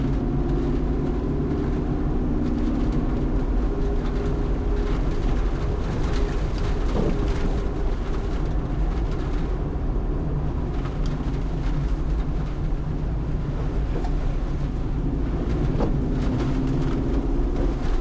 12_1_ezdy na maschiny idet dojd.ogg